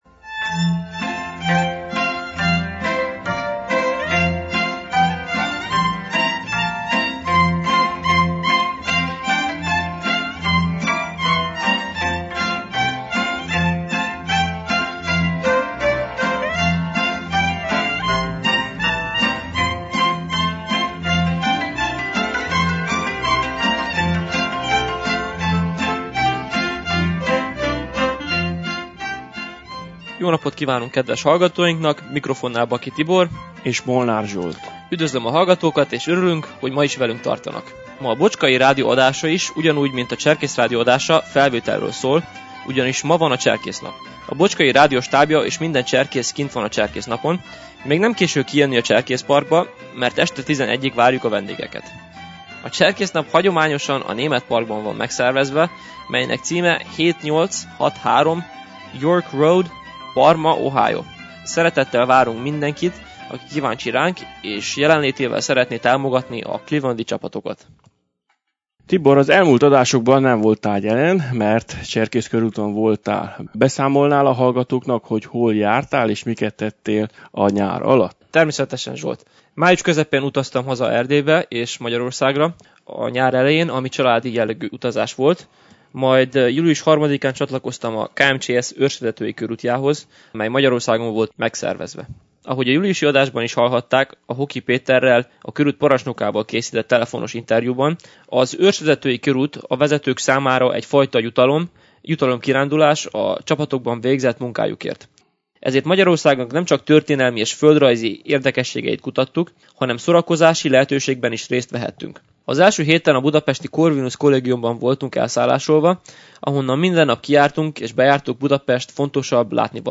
Beszámoló a Vezető Képző táborról